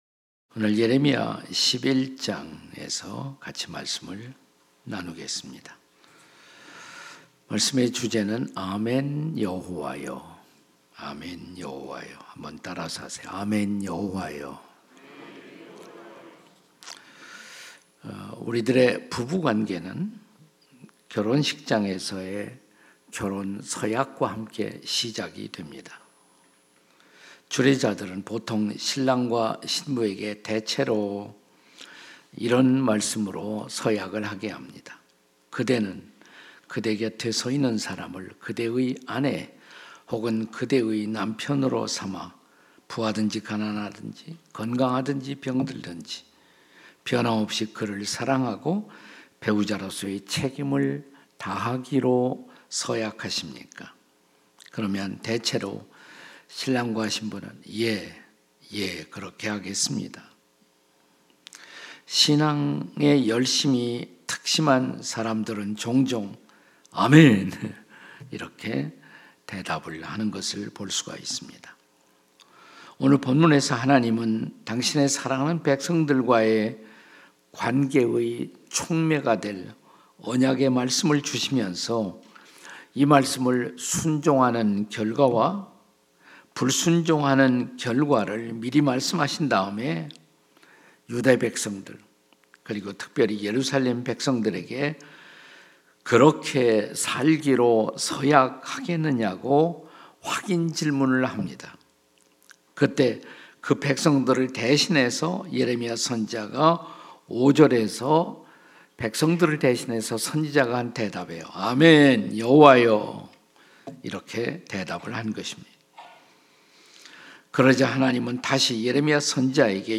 설교 : 주일예배 예레미야 - (10) 아멘, 여호와여! 설교본문 : 예레미야 11:1-5, 12:5